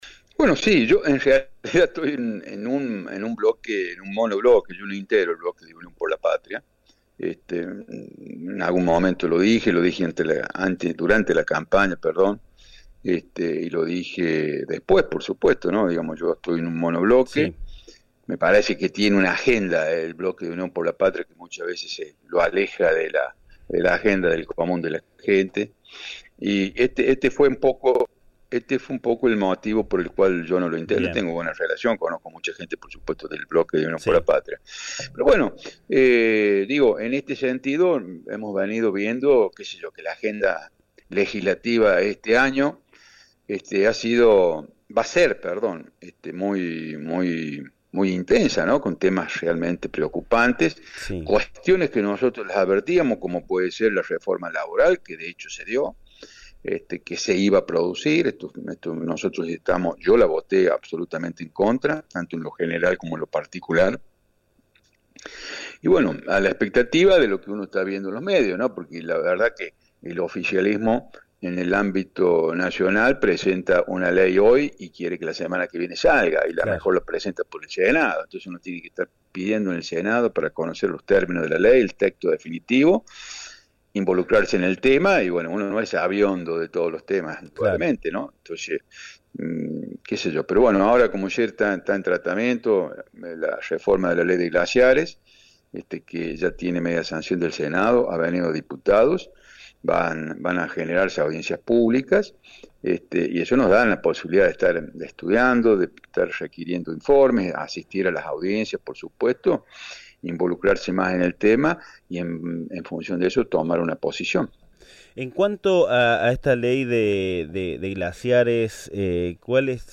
En diálogo con Acento Mercedino por Radio UNSL Villa Mercedes 97.5 FM, el diputado nacional Jorge Fernández analizó la intensa agenda legislativa que se debate en el Congreso y expresó su preocupación por diversas reformas impulsadas por el Gobierno nacional.